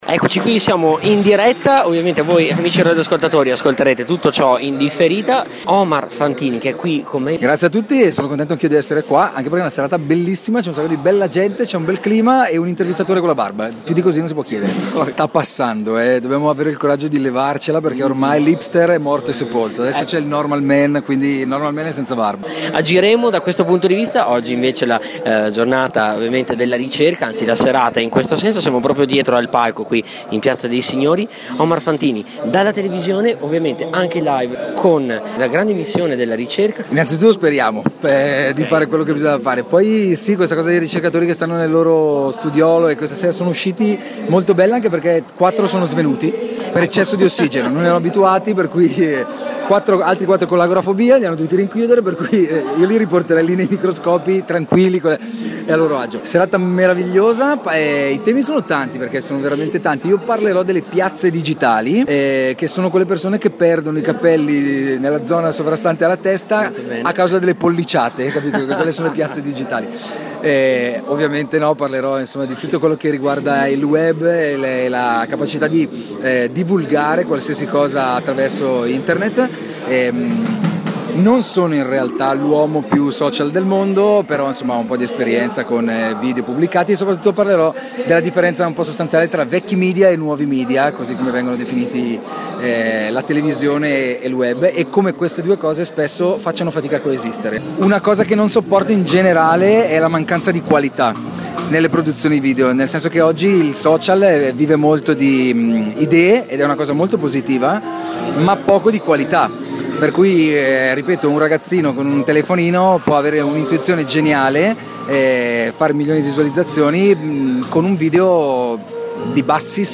Abbiamo intervistato per voi Omar Fantini, presentatore della serata e comico televisivo, e Massimo Cotto, tra le personalità di spicco della radiofonìa Italiana e della tv.